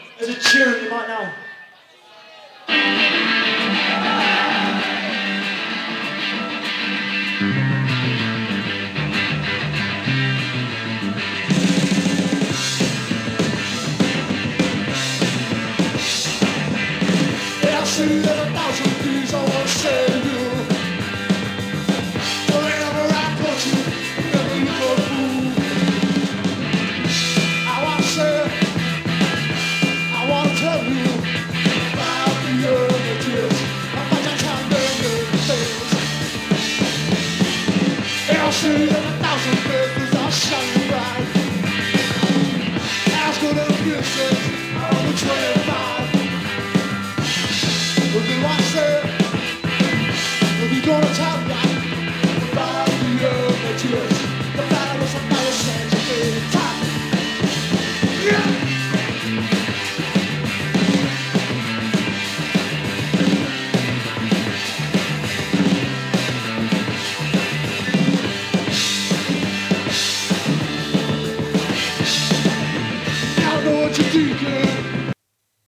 Live CD ネオモッズ
シールド新品 (試聴はLPからの流用)。